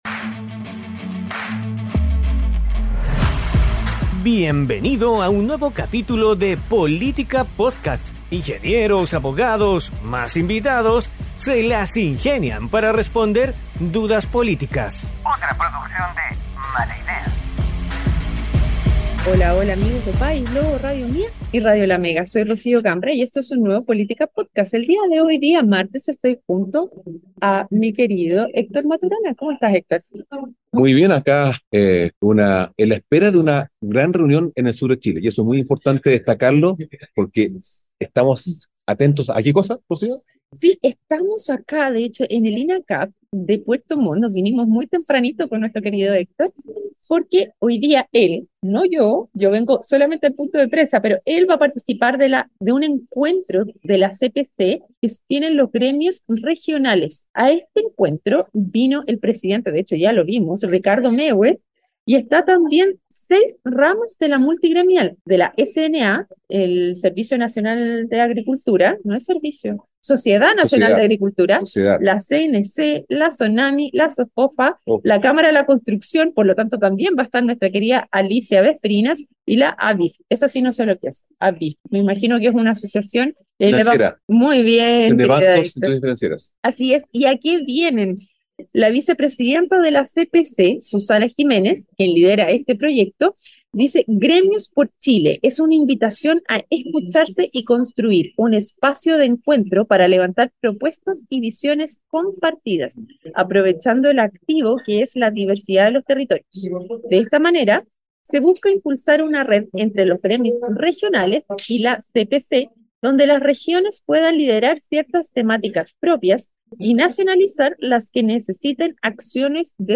junto a un panel de expertos estables e invitados especiales